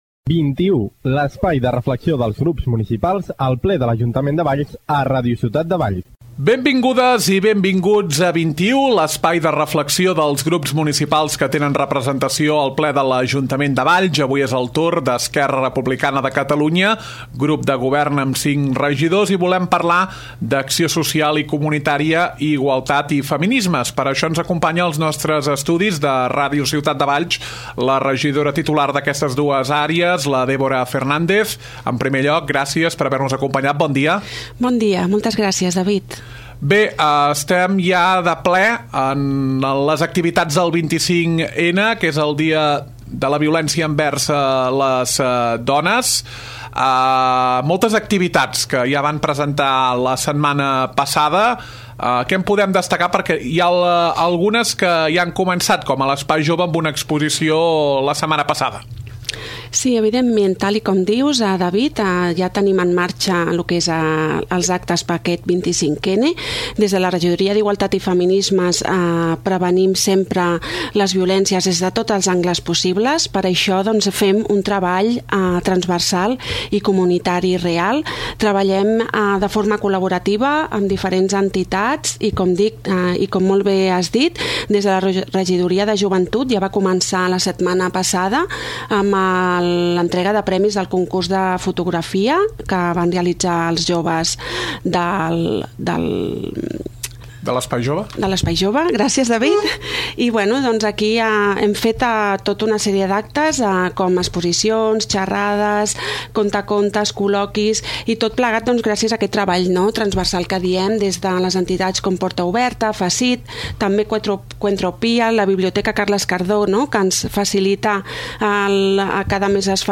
Entrevista a Dèbora Fernández, regidora d’Acció Social i Comunitària; i Igualtat i Feminismes.